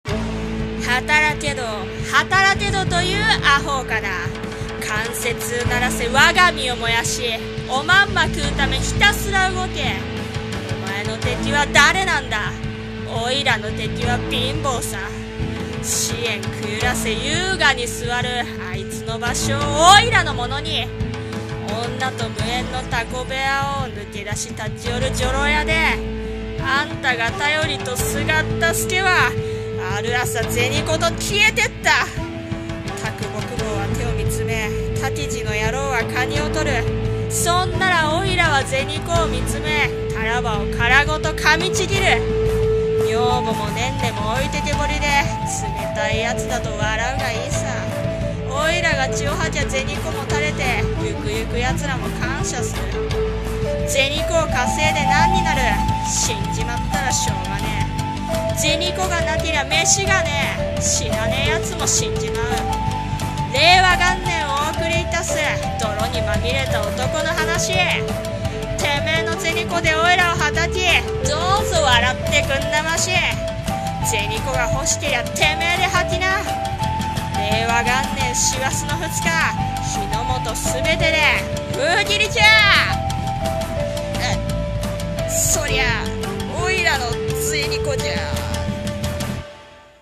さんの投稿した曲一覧 を表示 【映画予告風】銭コが欲しけりゃテメェで吐きな【朗読】